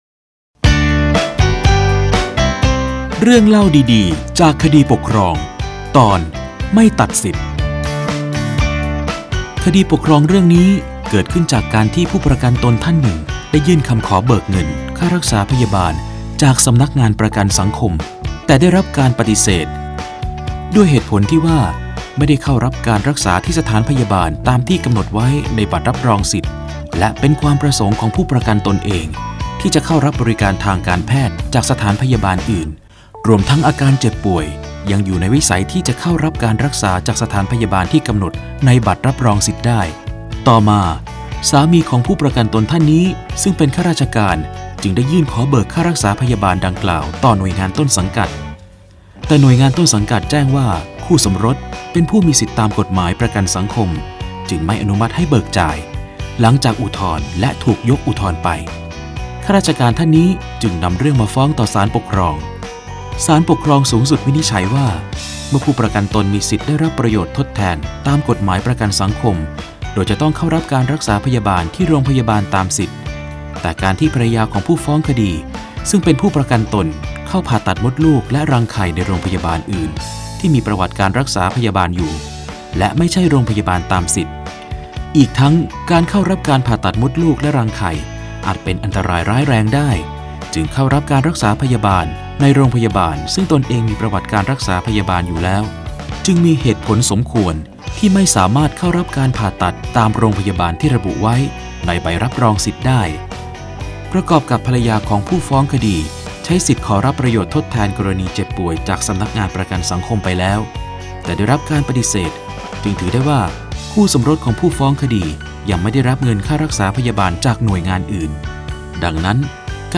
สารคดีวิทยุ ชุด เรื่องเล่าดีดีจากคดีปกครอง - ไม่ตัดสิทธิ
ลักษณะของสื่อ :   คลิปการเรียนรู้, คลิปเสียง